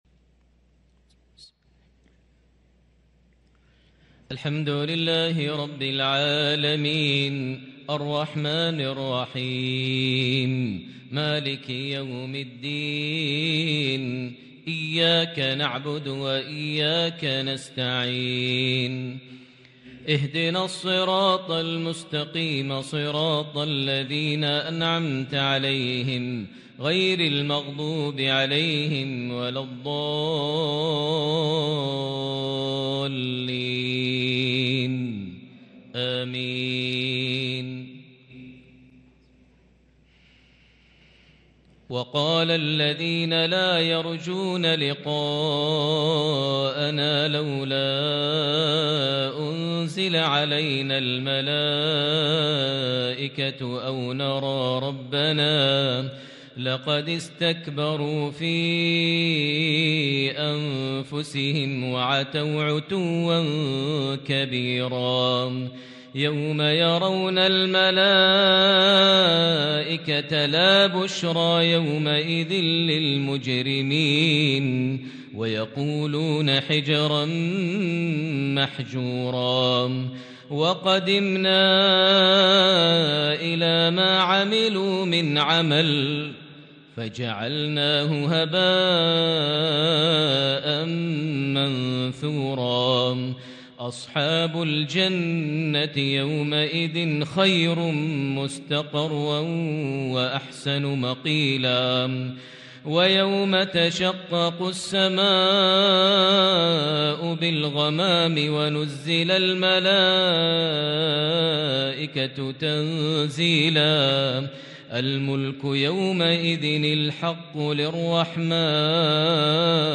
Isha prayer from Surah al-Furqan 4-2-2022 > 1443 H > Prayers - Maher Almuaiqly Recitations